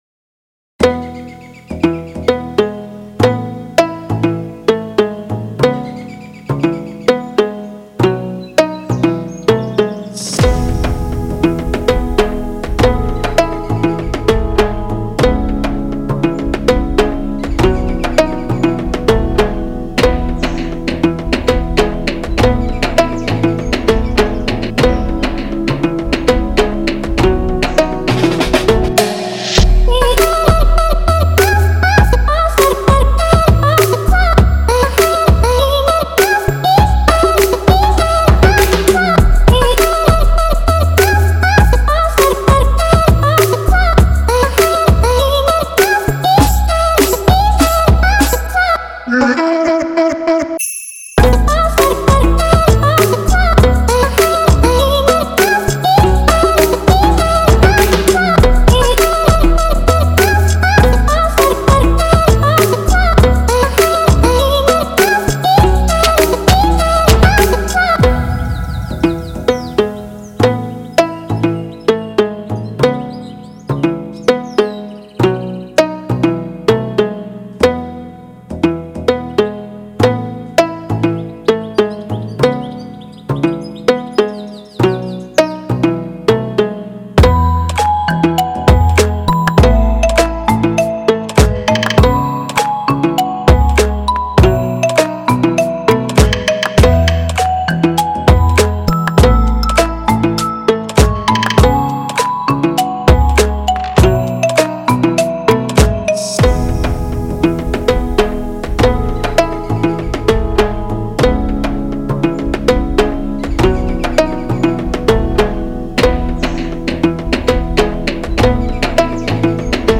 他的声音包含了Hard Bass，氛围类音效，环境声等等
音色试听
电音采样包